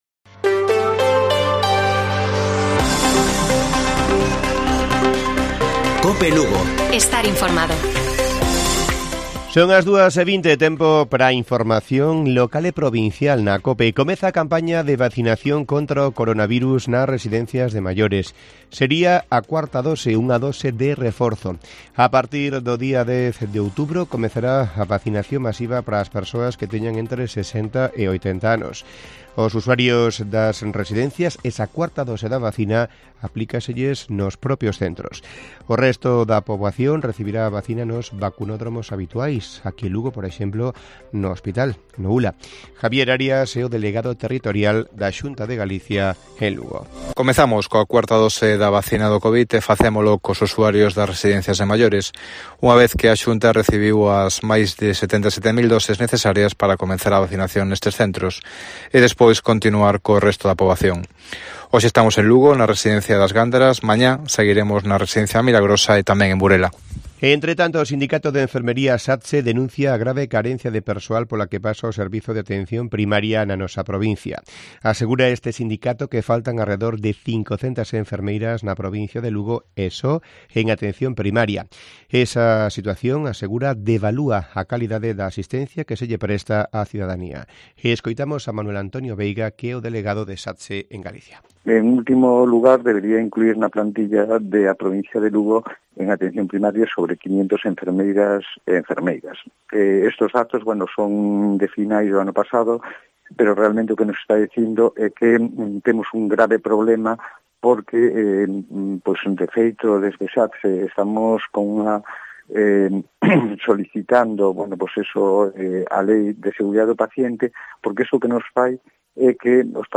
Informativo Mediodía de Cope Lugo. 26 de septiembre. 14:20 horas